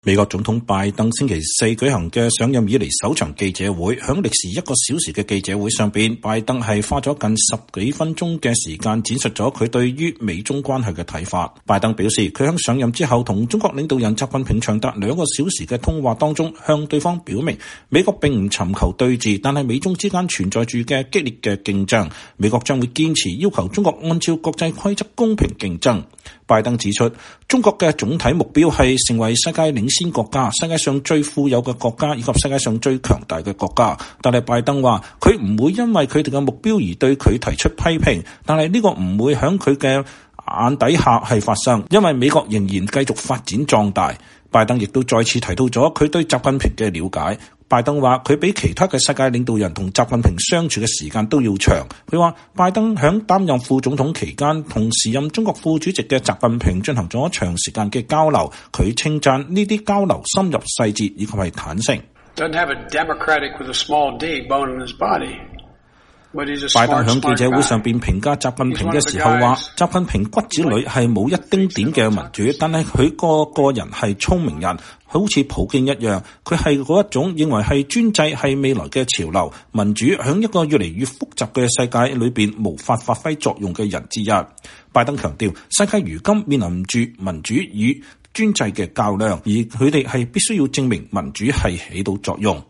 美國總統拜登星期四（3月25日）舉行了上任以來的首場記者會。在歷時一個小時的記者會上，拜登花了近十分鐘的時間闡述了他對於美中關係的看法。